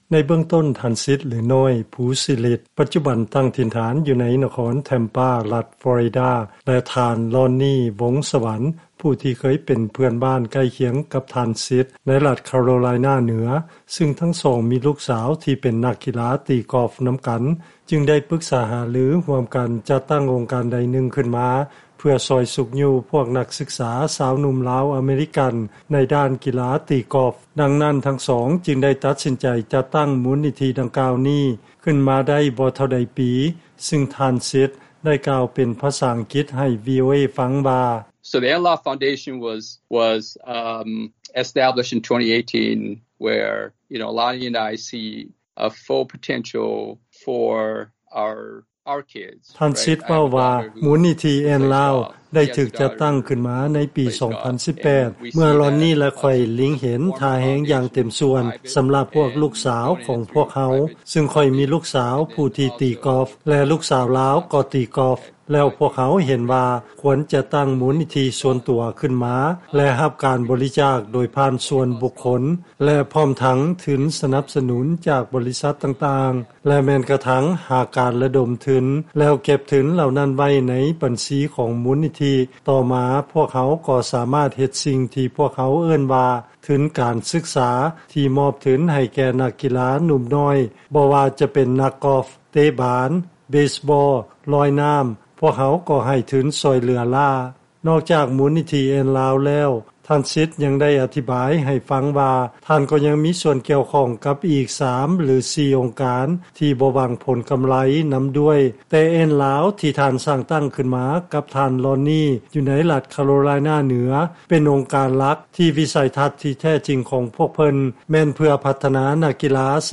ລາຍງານ